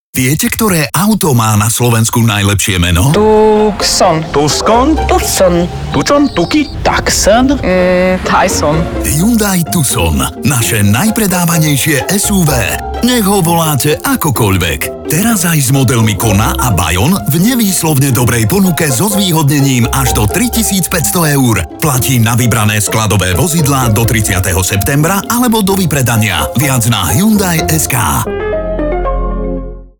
hyundai_tucson_radio-30_final.mp3